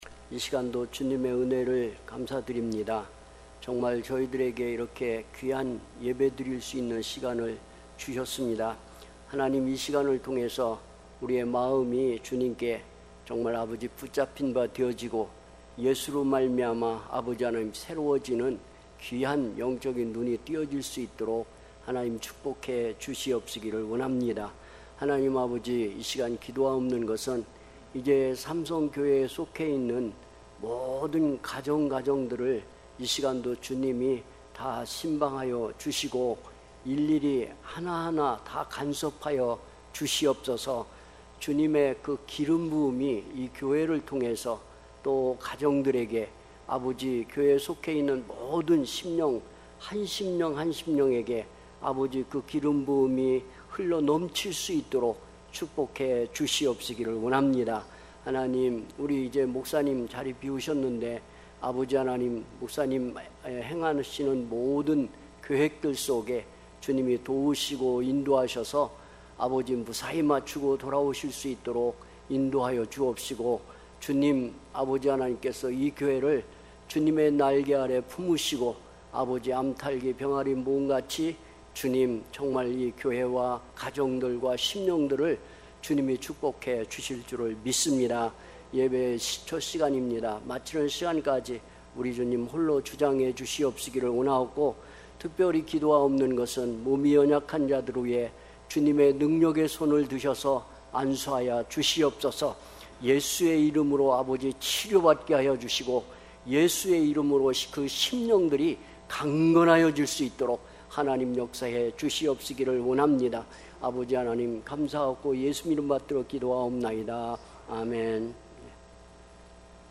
특별집회 - 로마서 8장 1-13절